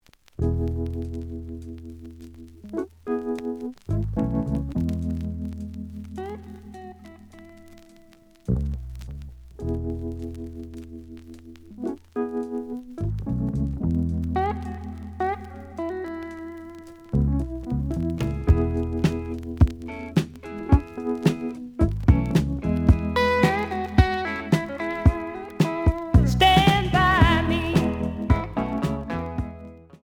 The audio sample is recorded from the actual item.
●Genre: Soul, 70's Soul
Slight noise on B side.)